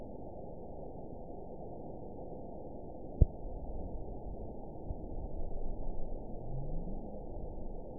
event 920327 date 03/16/24 time 18:24:33 GMT (1 year, 1 month ago) score 9.36 location TSS-AB01 detected by nrw target species NRW annotations +NRW Spectrogram: Frequency (kHz) vs. Time (s) audio not available .wav